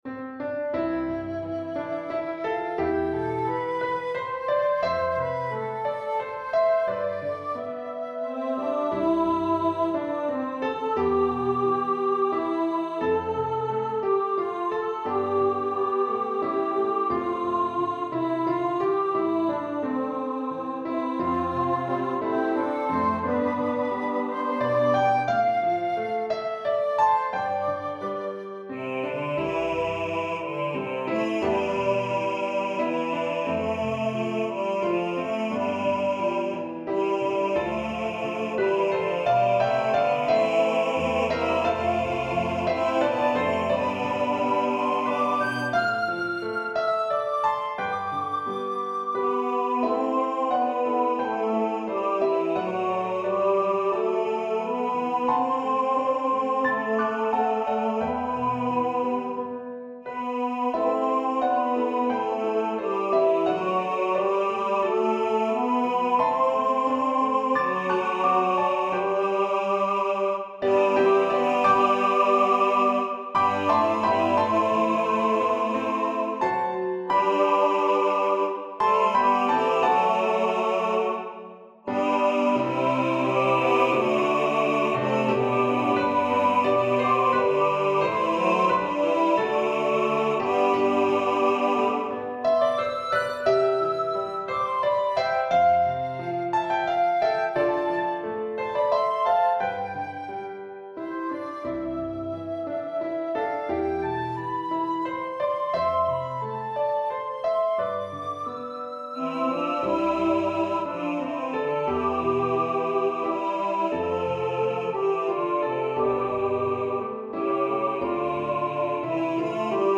for SATB Choir, Piano & opt. Flute
Director’s Note: This piece is in a simple A-B-A-B format.
AI MP3 file: